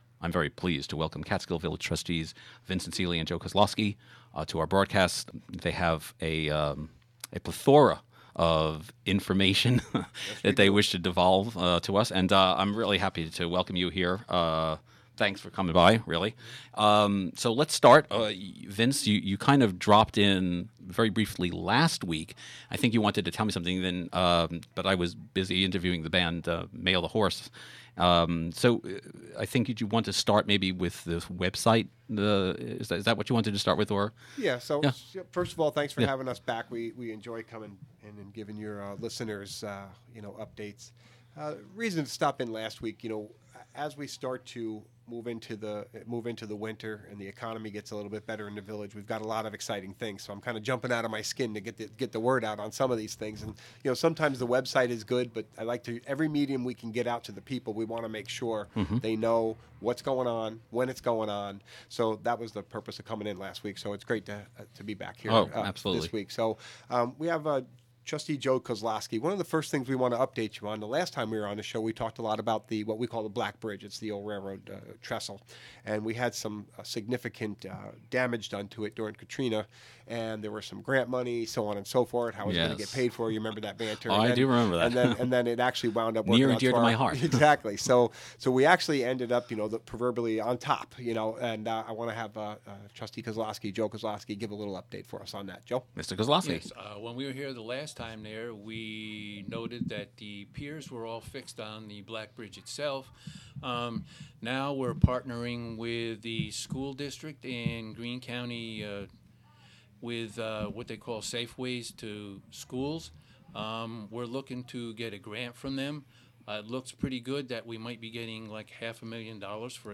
Interviewed